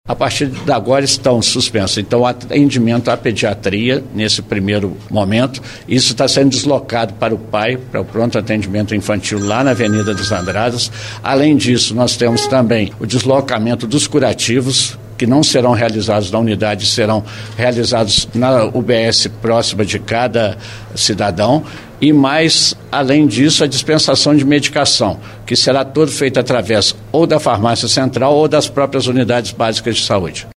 prefeito Antônio Almas (PSDB)